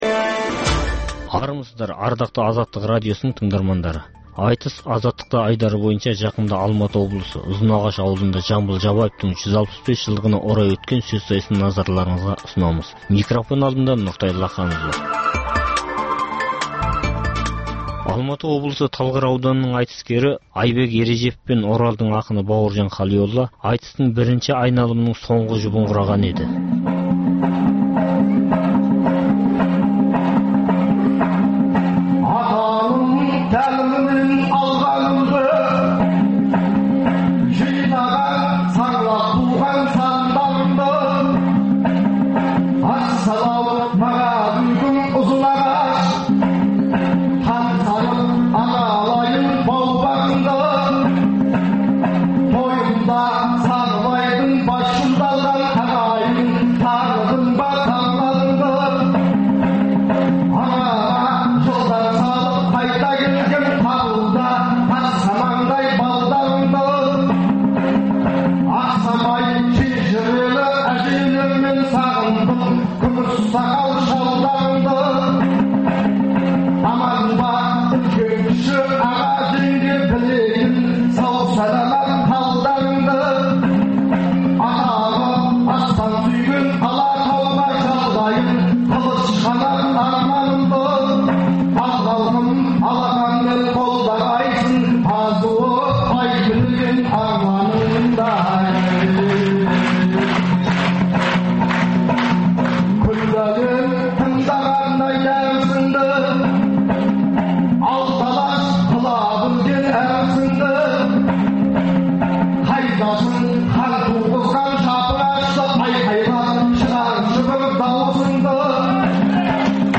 Айтыс - Азаттықта
Қазақстанда әр уақытта өткізілетін ақындар айтысының толық нұсқасын ұсынамыз.